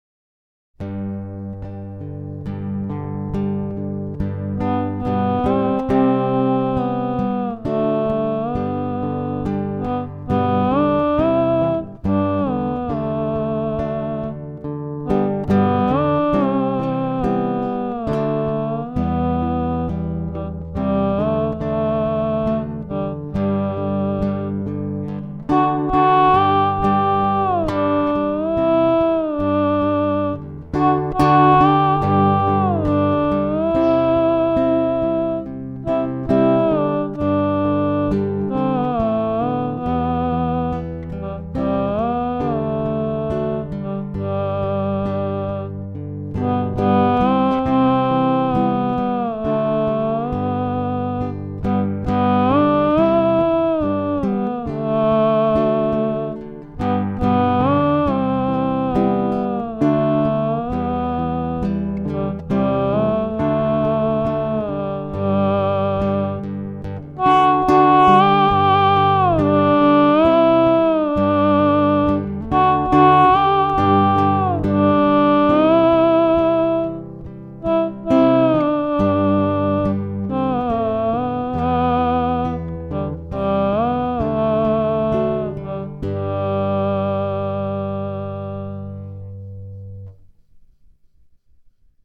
Me playing a hymn on guitar and D-Lev (preset 7, "patsy_tenor"):